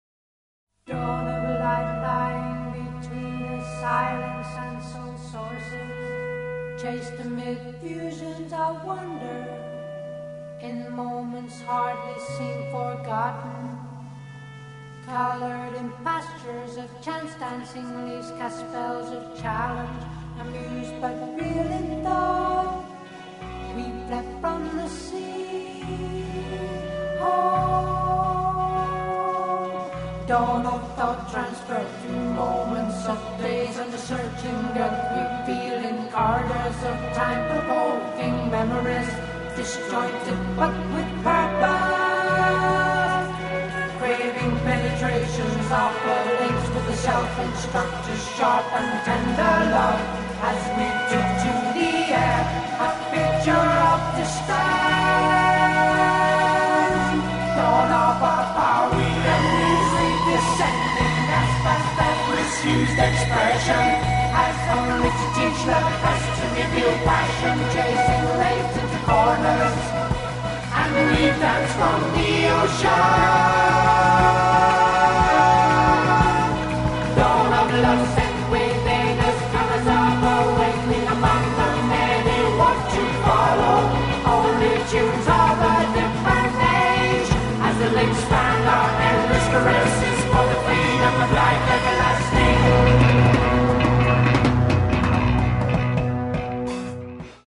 бас, бэк-вокал